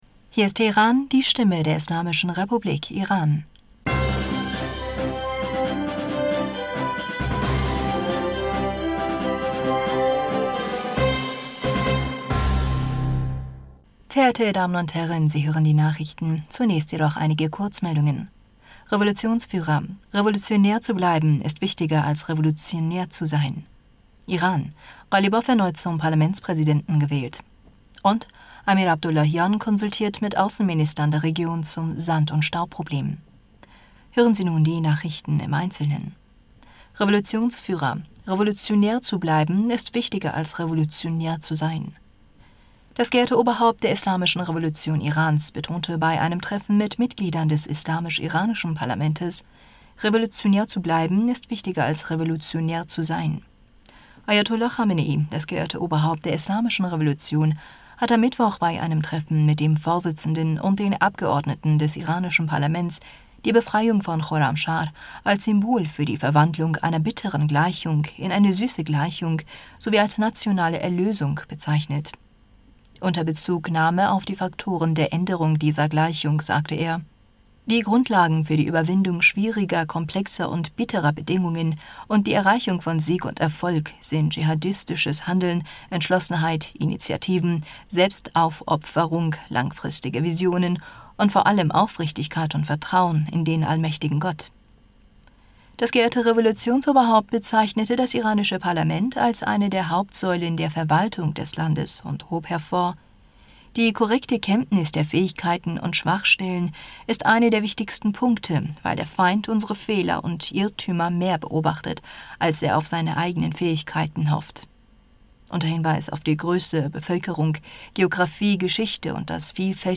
Nachrichten vom 25. Mai 2022